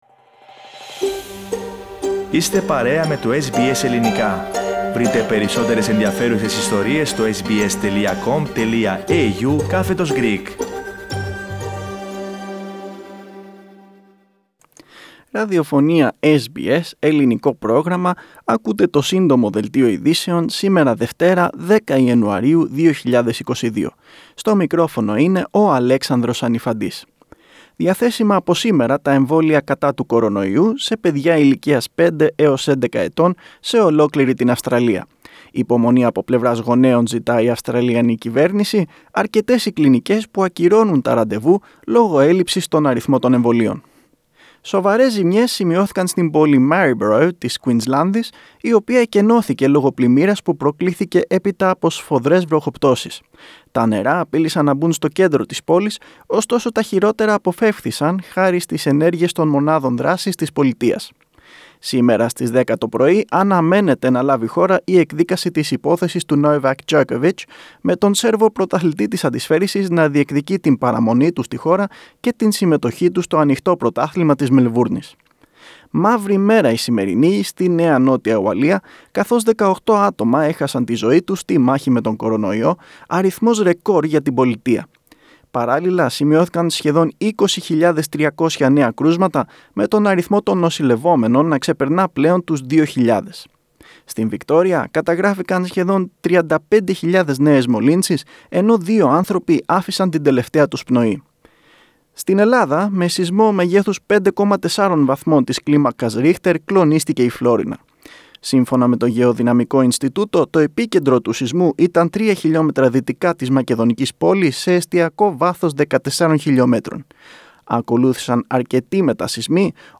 News Flash - Σύντομο Δελτίο
Source: SBS Radio